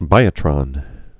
(bīə-trŏn)